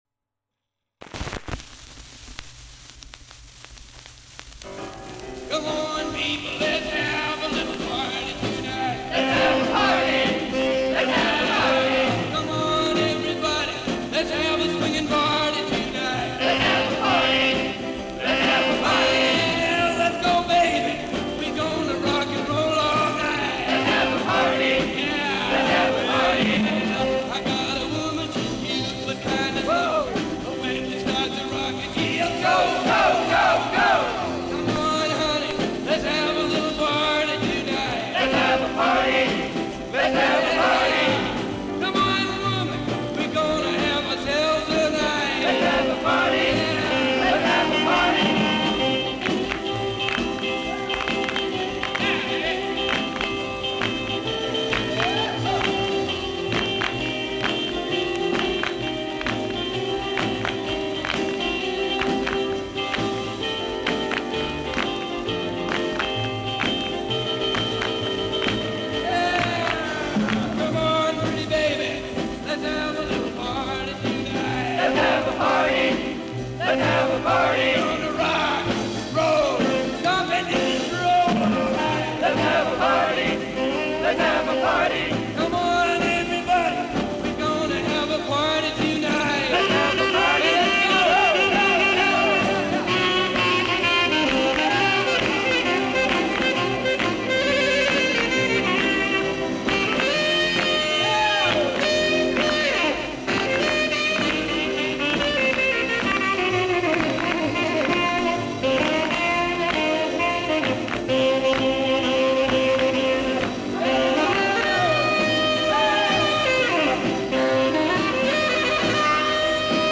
rock and roll band
lead vocals
on sax